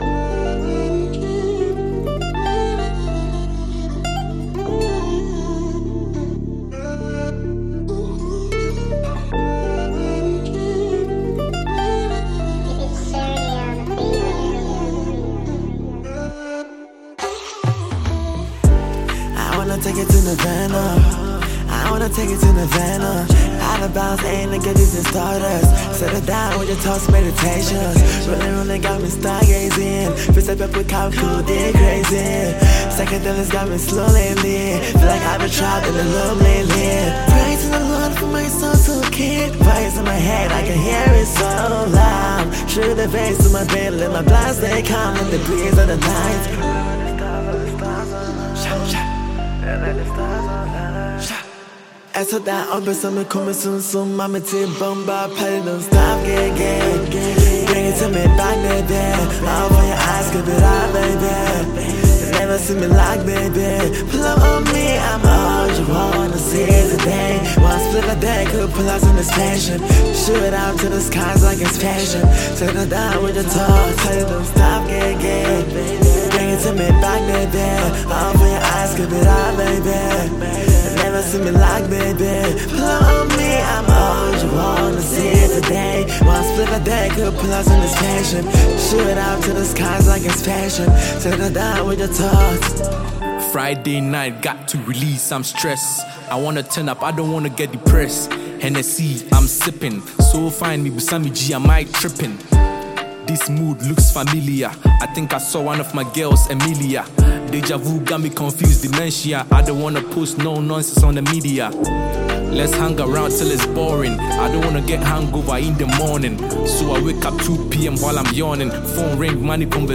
a Ghanaian drill musician